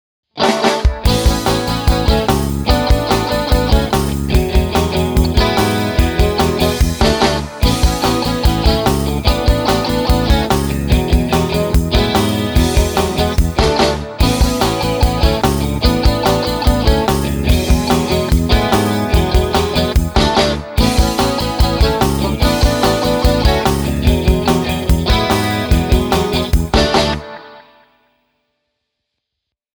akai-analog-delay-chorus.mp3